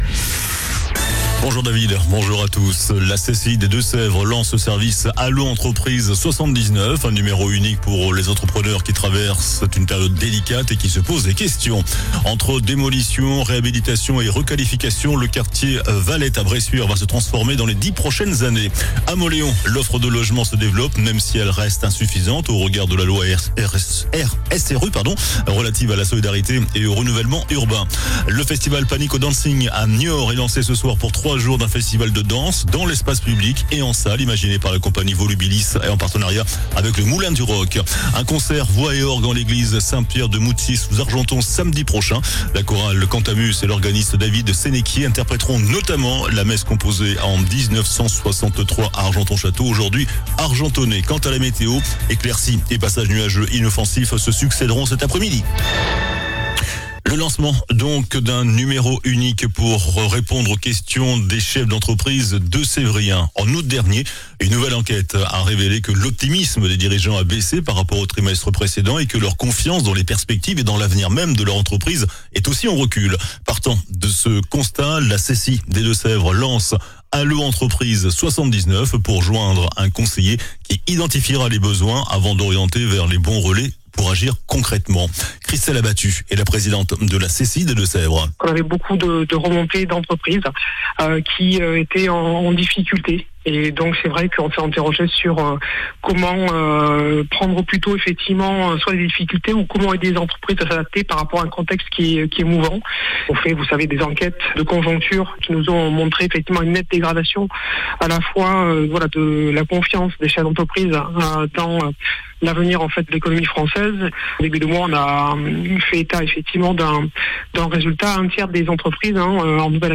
JOURNAL DU JEUDI 25 SEPTEMBRE ( MIDI )